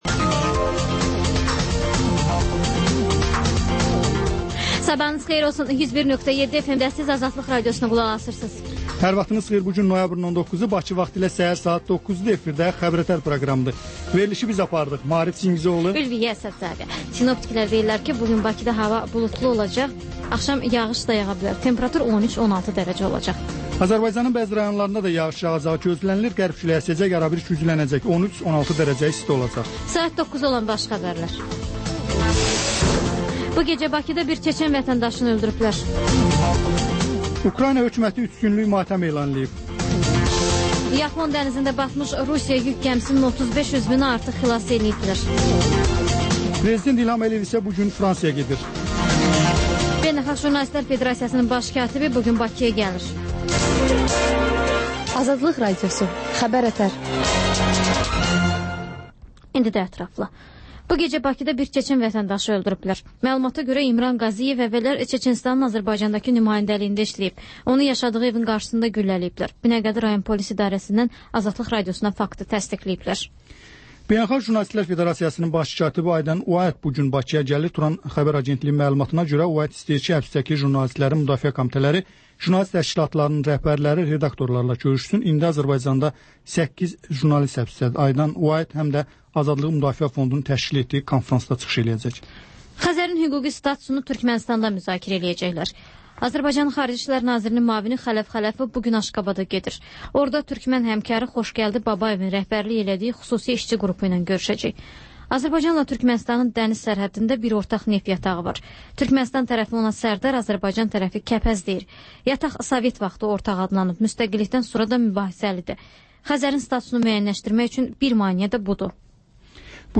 Xəbərlər, müsahibələr.